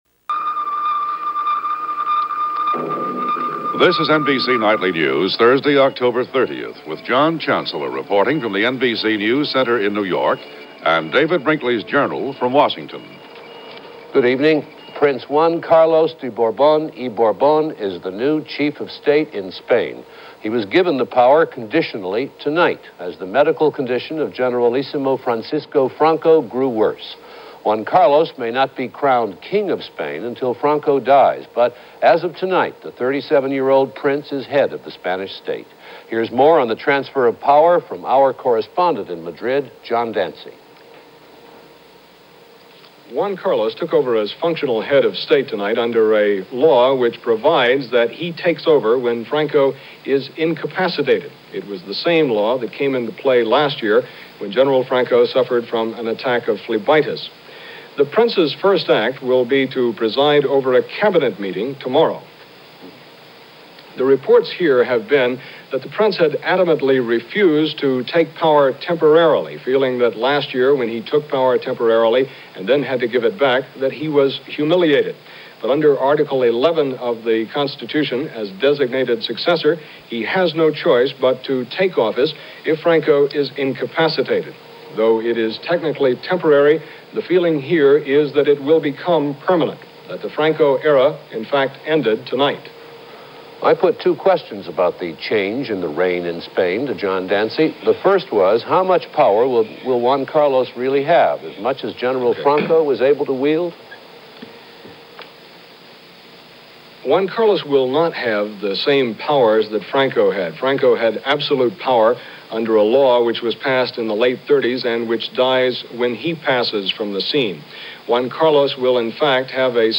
– NBC Nightly News – October 30, 1975 –